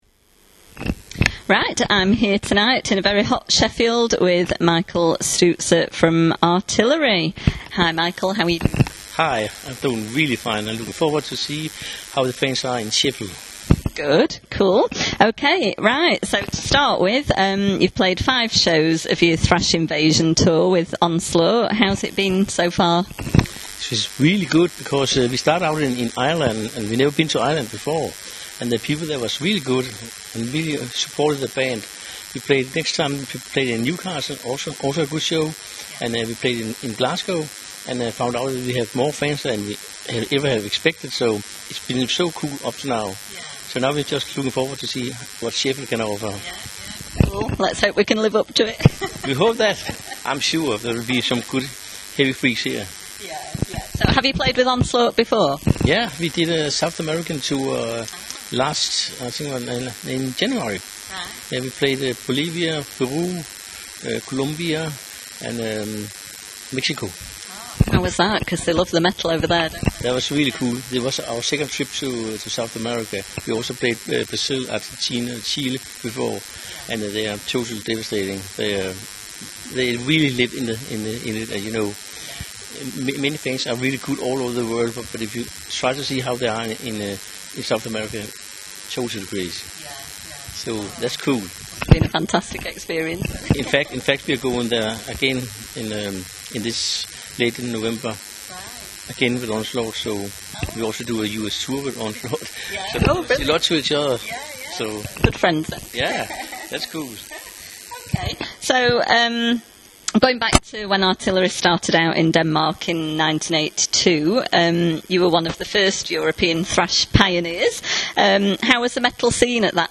Interview
To listen to the interview in full click on the audio player below, apologies for the interference on the recording!